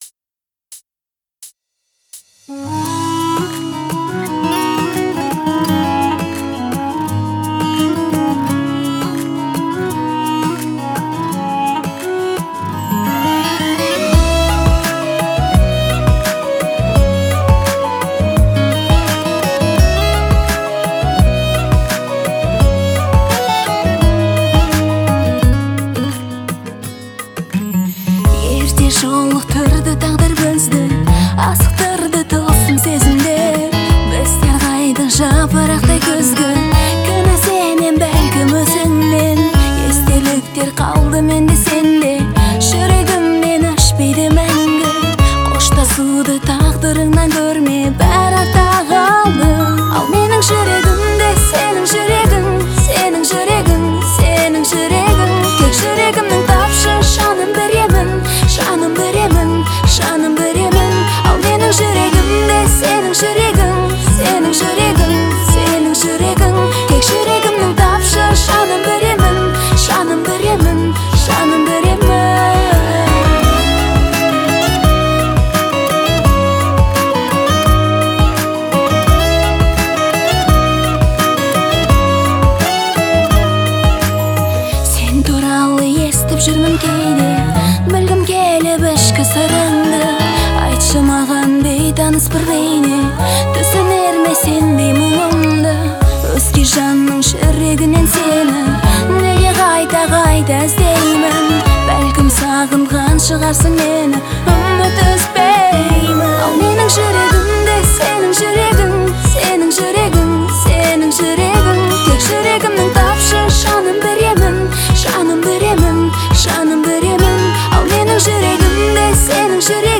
трогательную балладу в жанре казахской поп-музыки